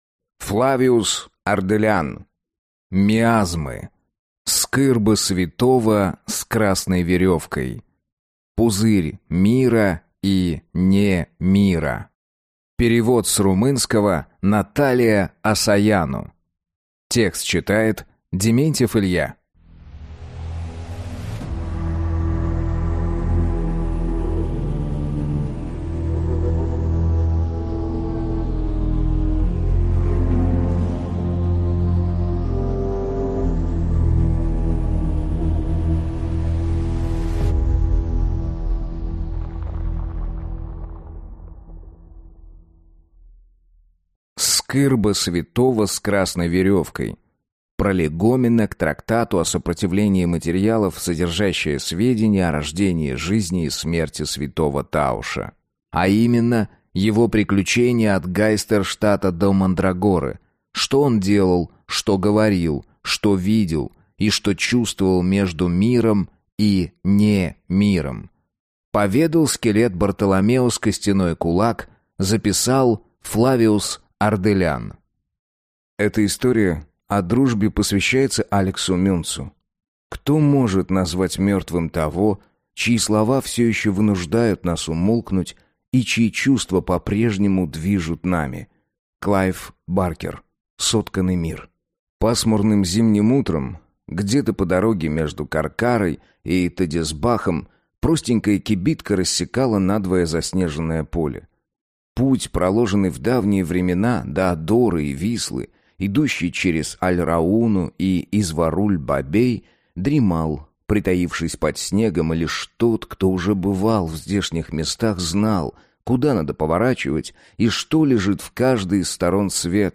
Аудиокнига Скырба святого с красной веревкой. Пузырь Мира и не'Мира | Библиотека аудиокниг